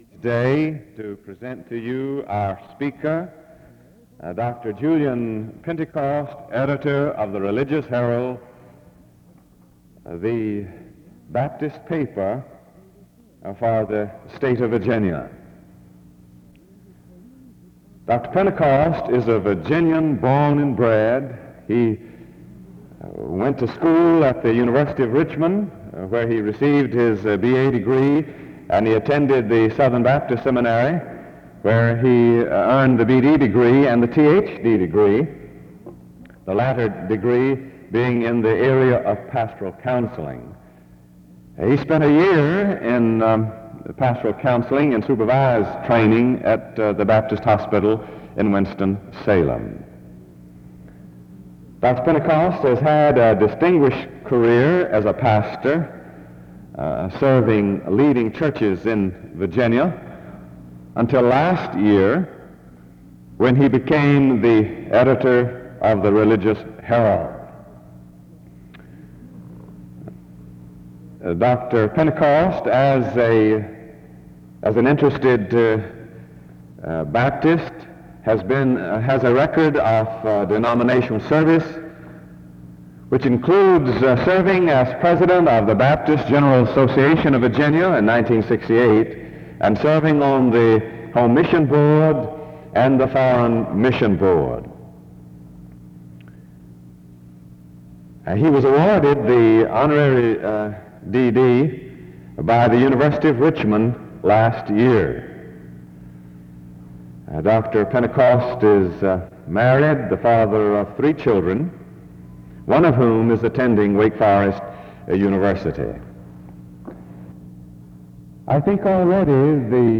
He examines the contentment believers often have with mediocre attitudes (4:40-9:38). He challenges his audience not to neglect the inward journey (9:39-15:20). In addition, he explains that God uses His church in order to show his love to the world (15:21-20:43). He concludes by reading a portion of a hymn (20:44-21:45). The service closes in prayer (21:46-22:16).